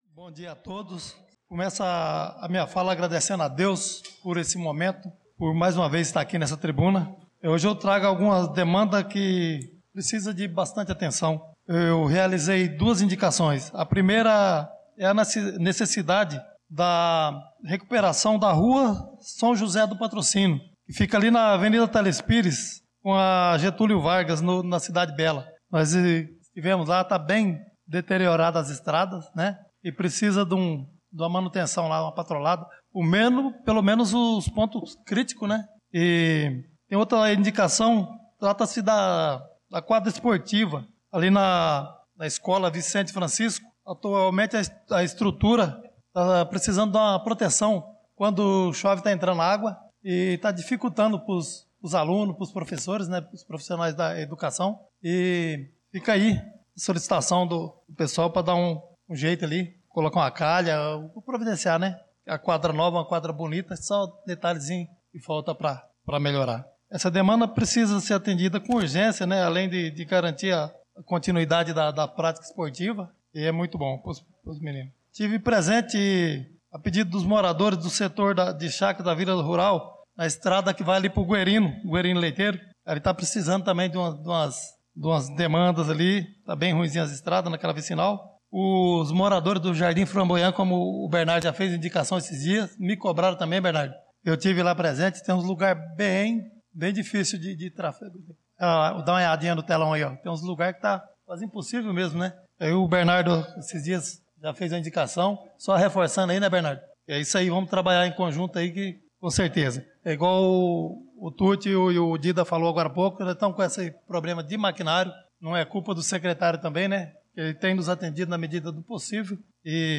Pronunciamento do vereador Chicão Motocross na Sessão Ordinária do dia 11/03/2025